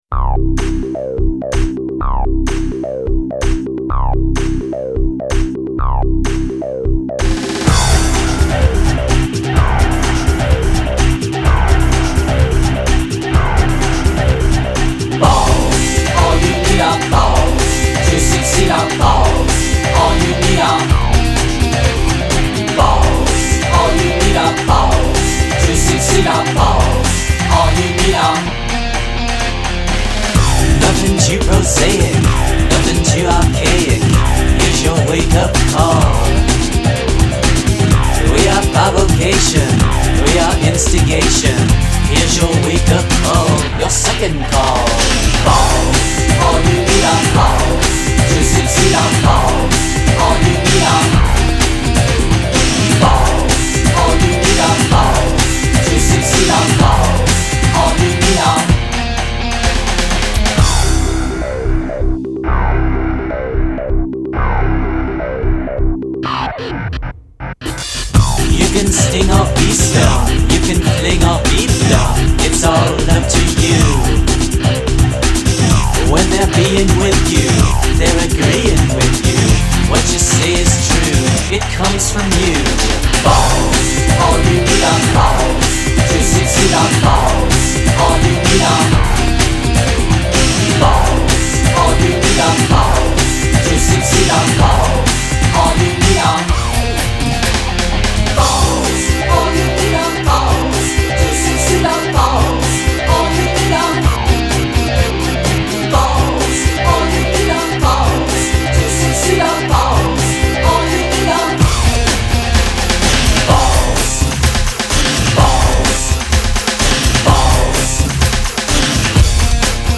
Genre : Rock, Pop